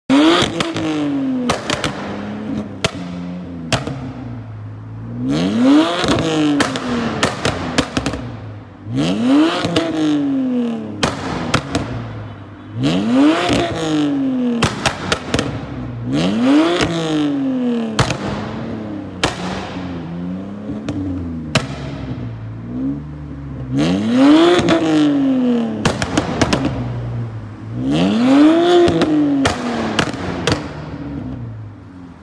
bmw e90 330i stage sound effects free download
bmw e90 330i stage 1 crackles map